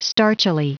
Prononciation du mot starchily en anglais (fichier audio)
Prononciation du mot : starchily